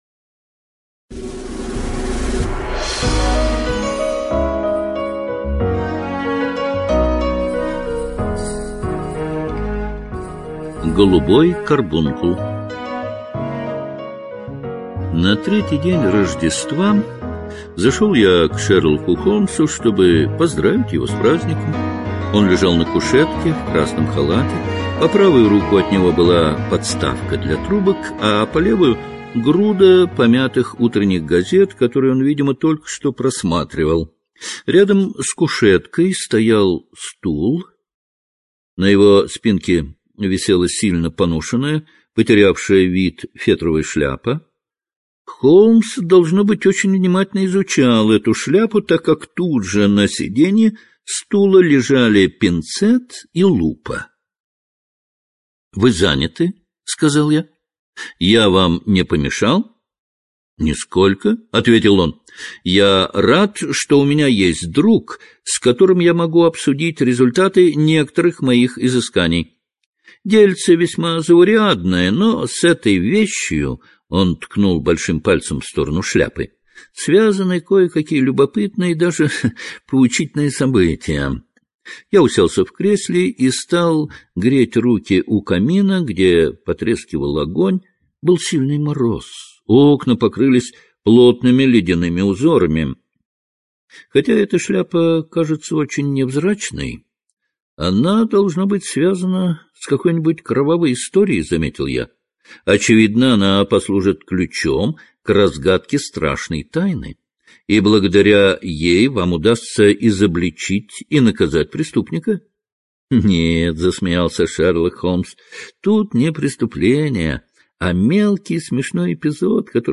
Голубой карбункул — слушать аудиосказку Артур Конан Дойл бесплатно онлайн